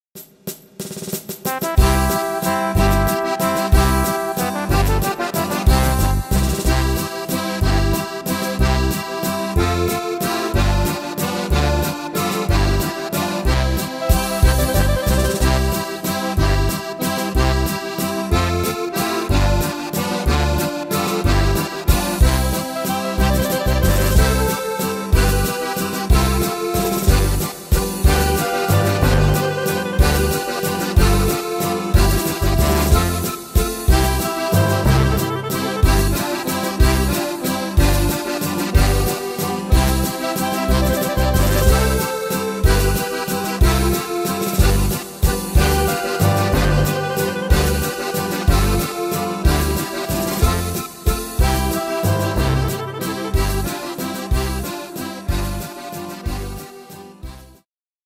Tempo: 185 / Tonart: C-Dur